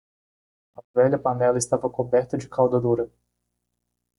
Pronounced as (IPA) /ˈkaw.dɐ/